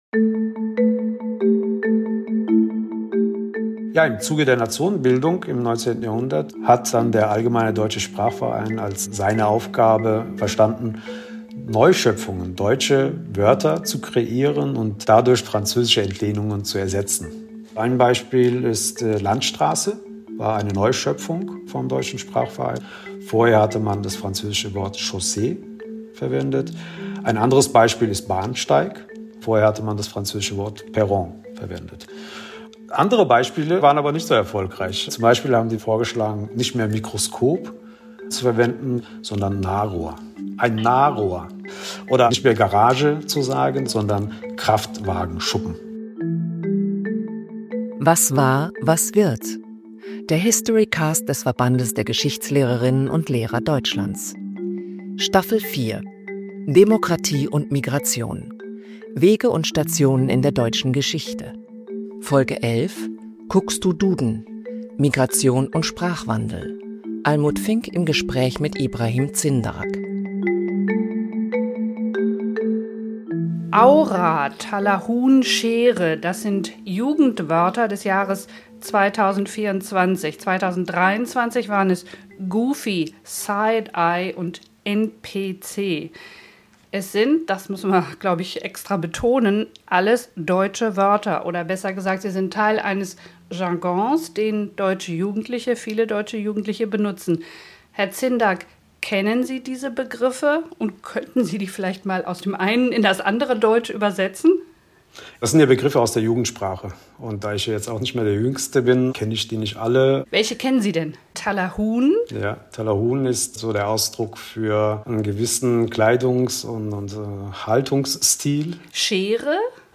Der Podcast beleuchtet, wie Migration schon immer zum Wandel von Sprache beigetragen hat – von französischen Lehnwörtern zur Zeit der Einwanderung der Hugenotten bis zur Gegenwart. Es wird diskutiert, wie Code-Switching sowie neue urbane Sprechstile unser Verständnis vom Deutschen und auch das, was im Duden steht, nachhaltig verändert haben.